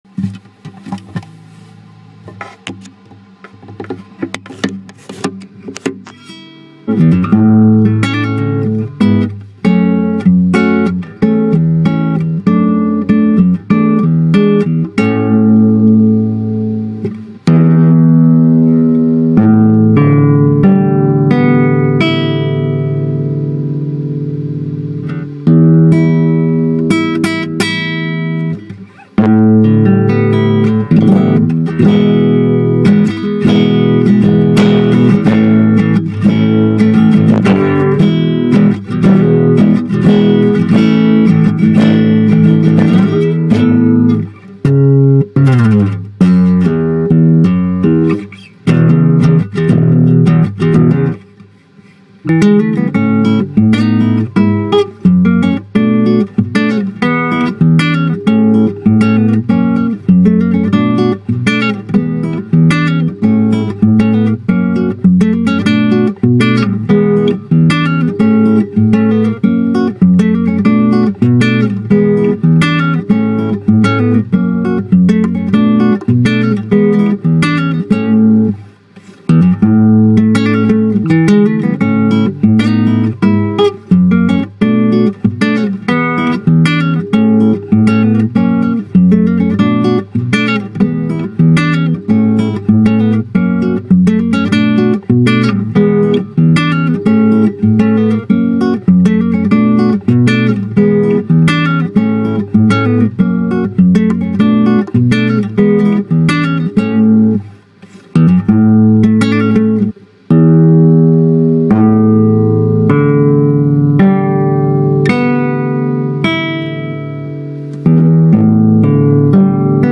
Guitar string oscillations
In the last part of the course, we solved the wave equation by decomposing the initial condition into basic nodes (eigenvectors of D2). Throwing a phone into a guitar and recording the strings shows some of the shapes which the string can take.
guitar.ogg